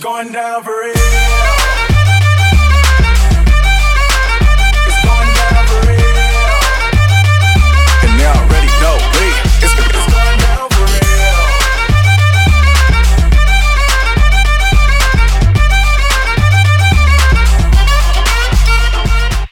• Качество: 192, Stereo
Миксованная версия намного более динамичная и живая